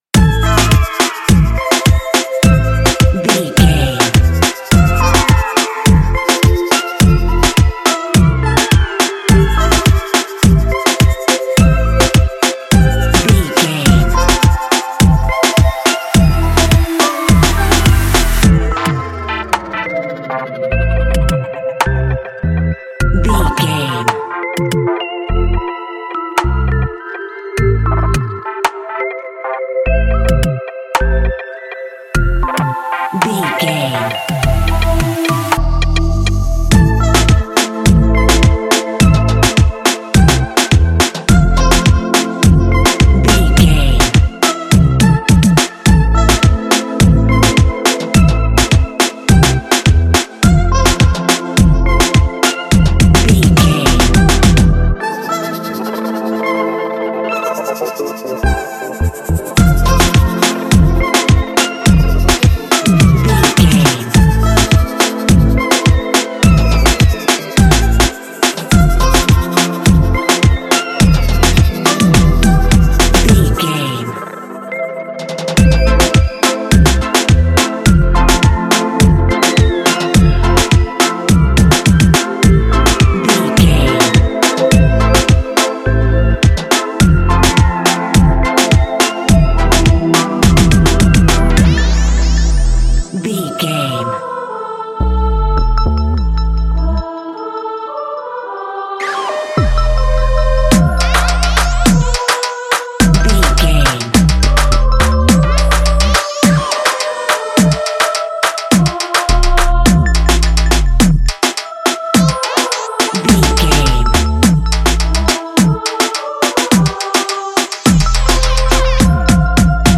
Dynamic reggaeton that invites action.
Ionian/Major
Ableton live
energetic
latino
powerful
party
catchy